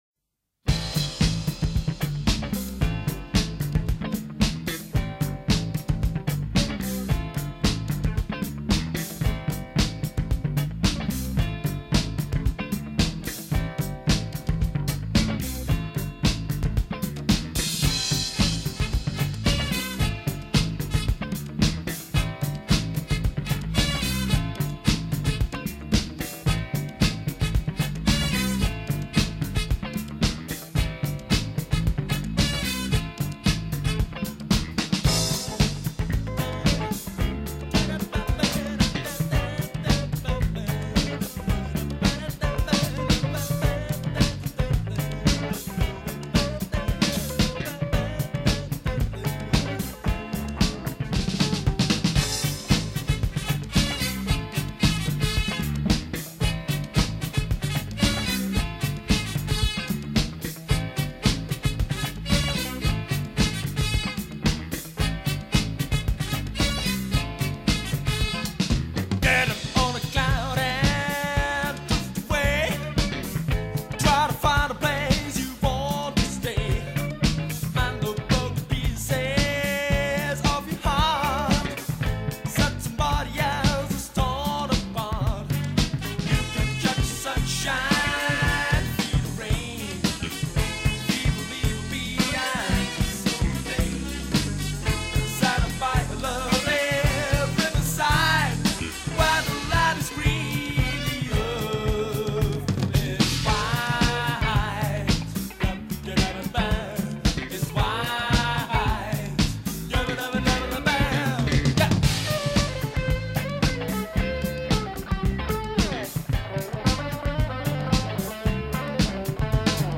tanzbarer Funk-Titel
Studio: Graffiti-Studio München 1983 Text und Musik